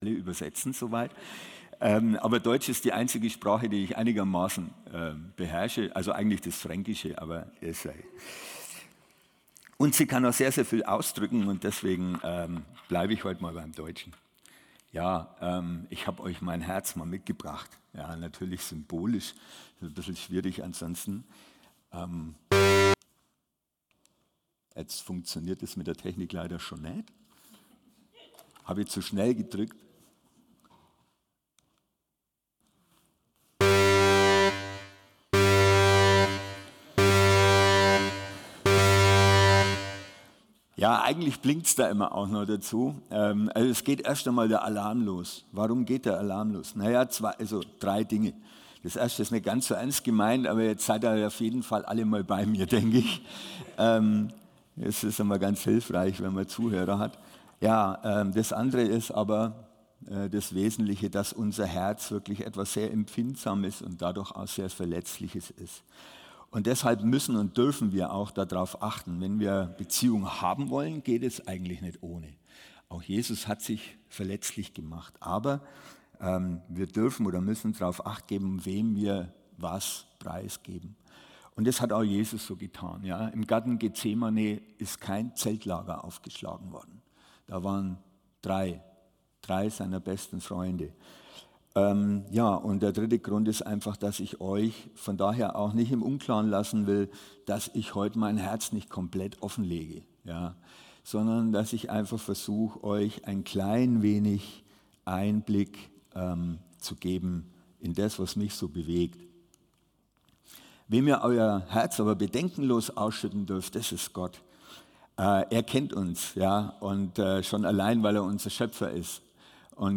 Hier erscheinen meist wöchentlich die Predigten aus dem Sonntags-Gottesdienst des CVJM Stuttgart